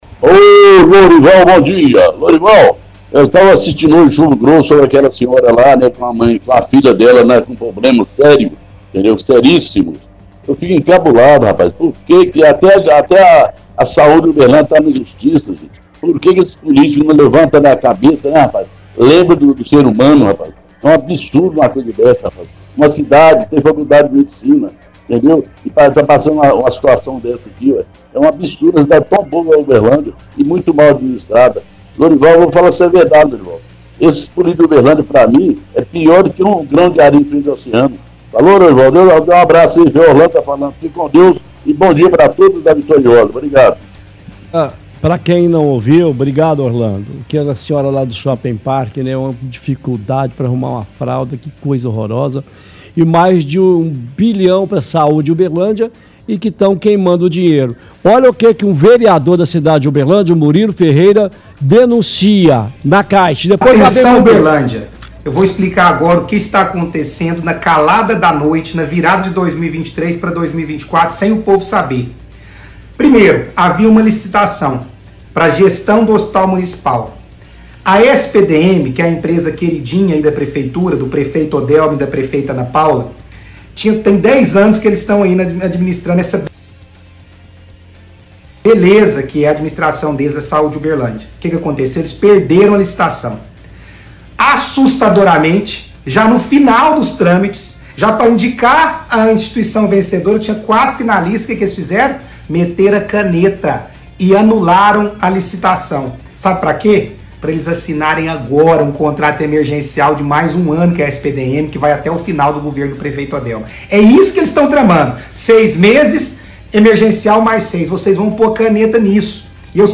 – Ouvinte fala sobre reportagem do Chumbo Grosso transmitida hoje ao afirmar que é um absurdo como a cidade é administrada.
– Transmissão de áudio do vereador Murilo falando que licitação, que não foi vencida pela SPDM, foi cancelada para poder fazer contrato emergencial com a empresa.